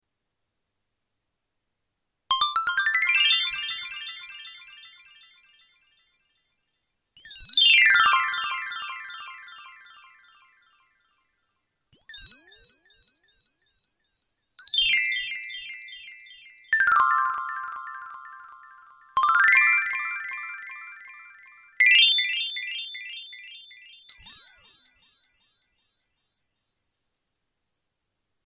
闪闪发光 闪亮登场